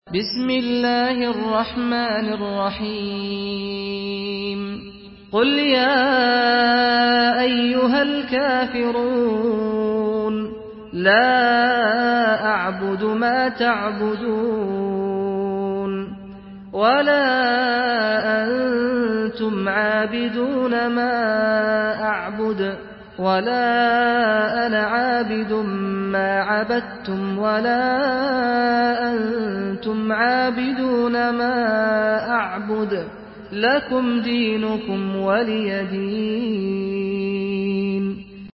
Surah Al-Kafirun MP3 by Saad Al-Ghamdi in Hafs An Asim narration.
Murattal Hafs An Asim